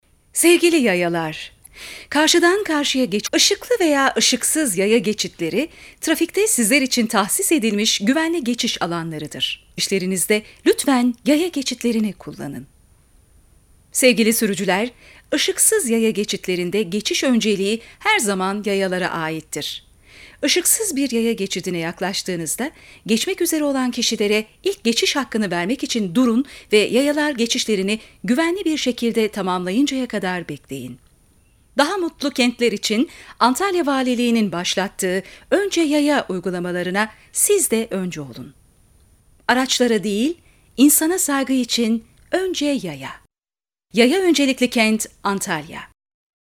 Radyolarda ve Toplu Etkinliklerde 'Önce Yaya' Kamu Spotları
Antalya Valiliği Avrupa Birliği Projeleri Koordiansyon Merkezi tarafından hazırlatılan ve biri kadın diğeri ekek ses ile sunulan kamu spotlarında okunan metin şöyle: